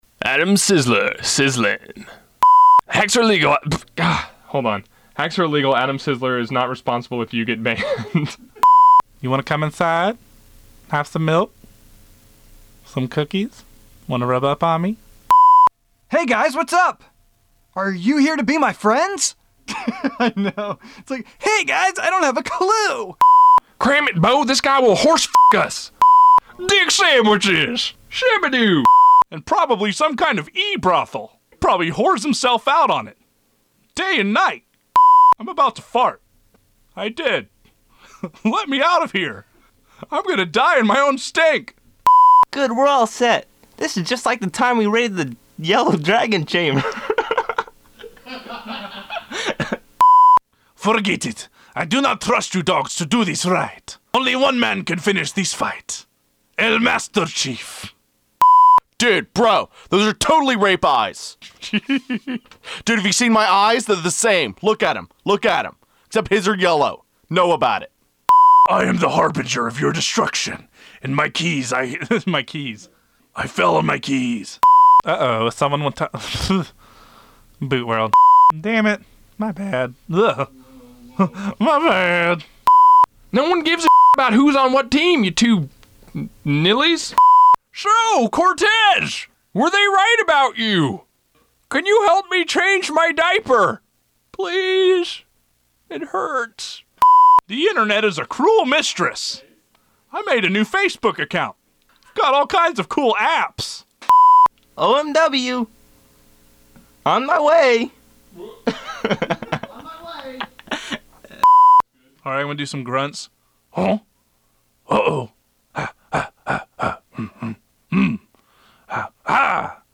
Second, I have the audio outtakes from Episode Eight.
To me, one of the best parts is when I’m doing the voice of the combine soldier (pre-effects, of course), and I slip into doing Cortez. I kind of freaked out when I did it, because it was so sudden.
tlw108outtakes.mp3